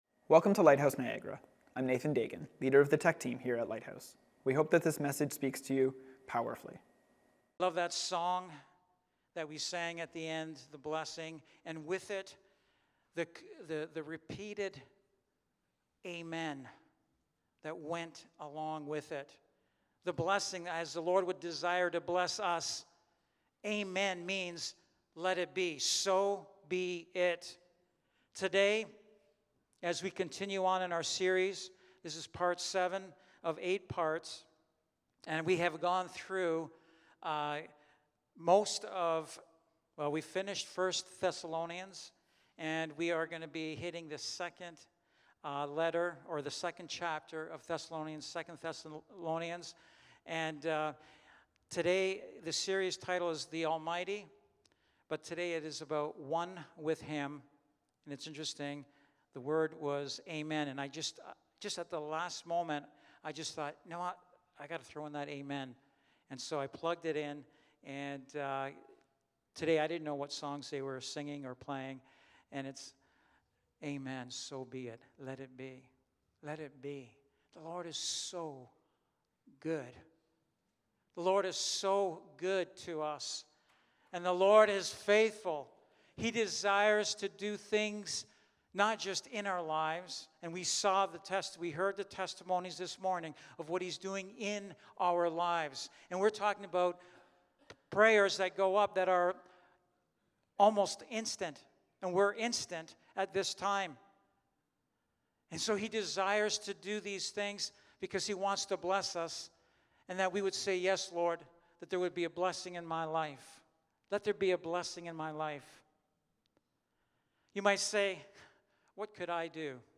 Lighthouse Niagara Sermons The Almighty: One With Him, Amen!